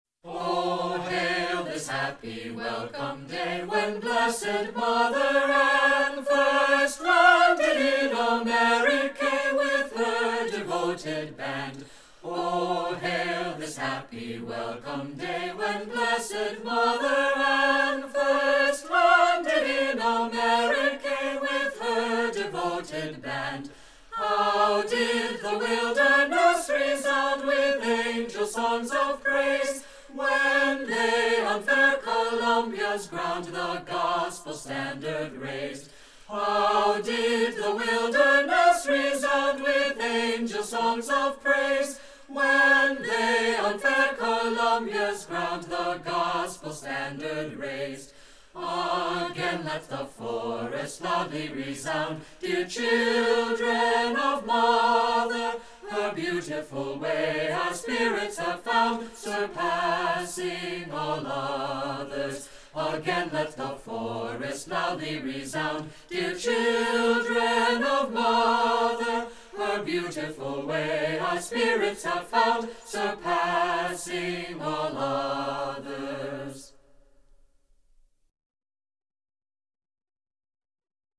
This hymn from North Union
hymn